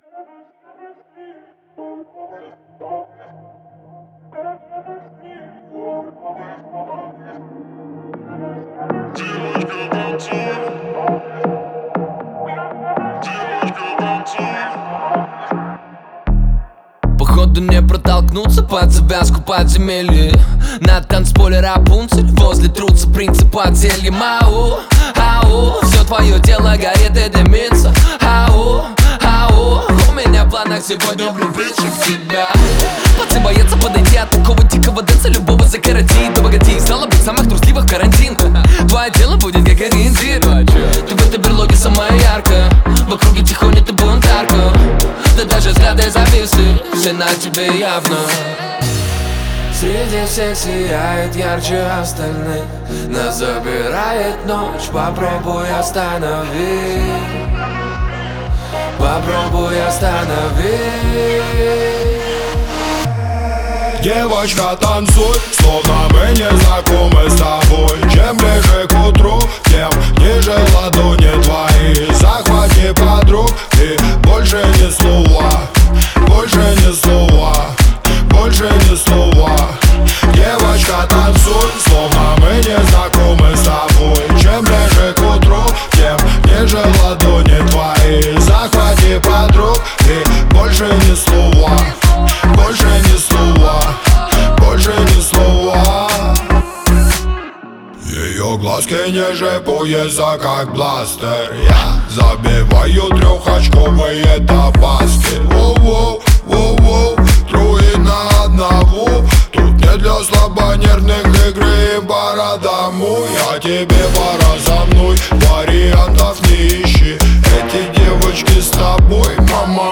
это трек в жанре хип-хоп